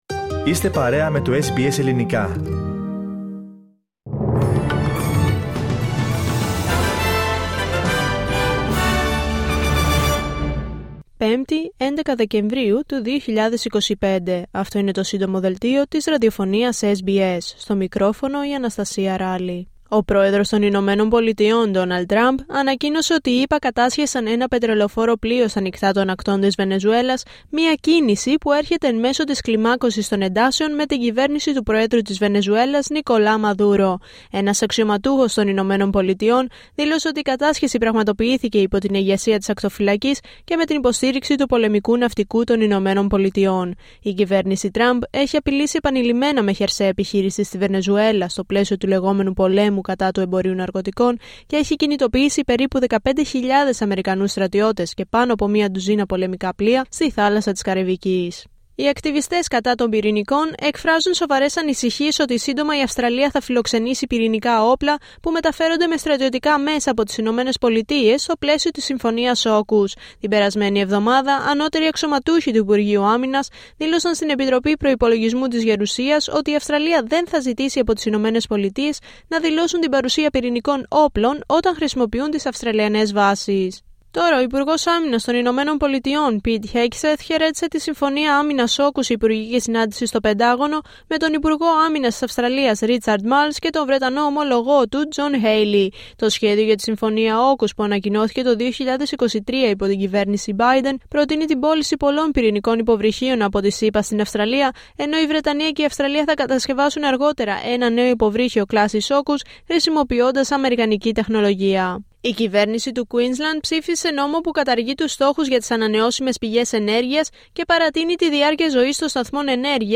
H επικαιρότητα έως αυτή την ώρα στην Αυστραλία, την Ελλάδα, την Κύπρο και τον κόσμο στο Σύντομο Δελτίο Ειδήσεων της Πέμπτης 11 Δεκεμβρίου 2025.